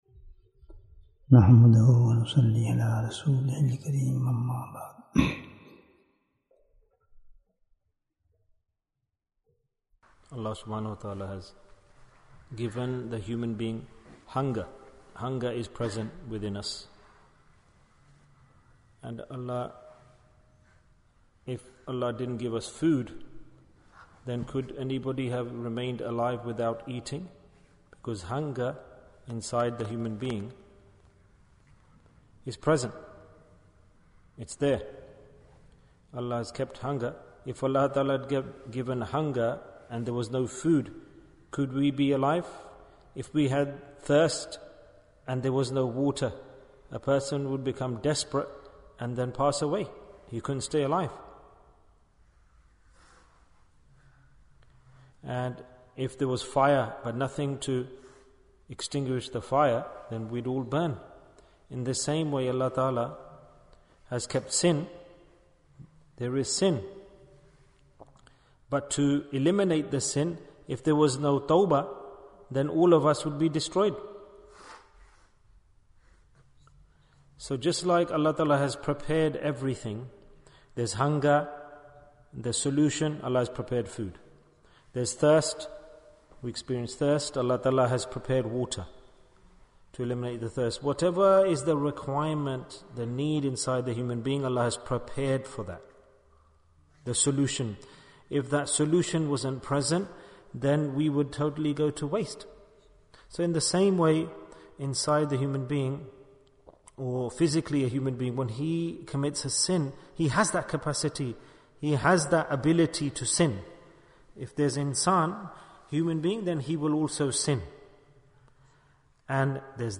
The Importance of the Second Asharah Bayan, 32 minutes2nd April, 2023